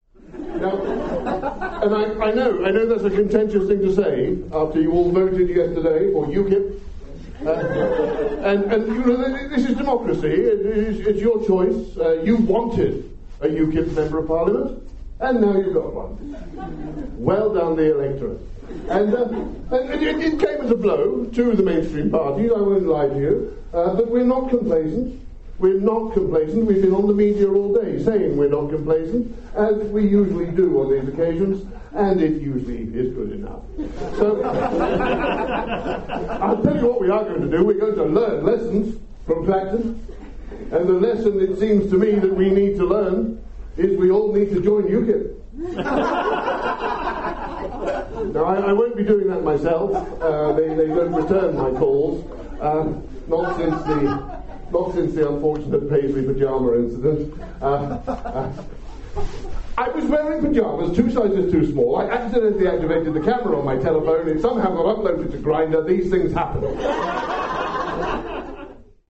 From a recent public meeting at which everyone agreed I was doing a splendid job: